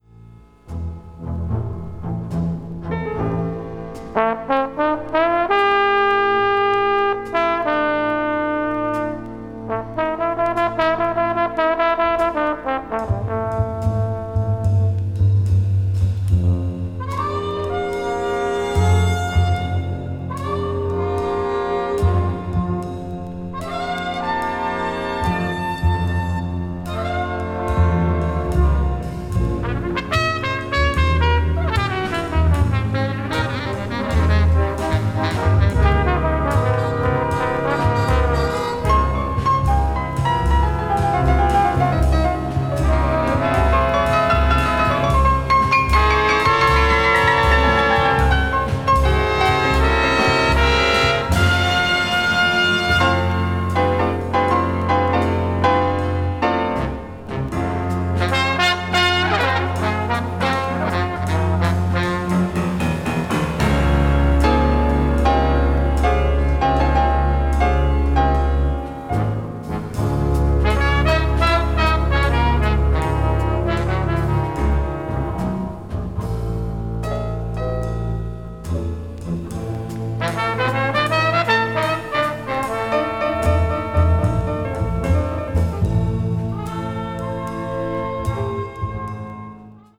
big band   contemporary jazz   modern jazz   post bop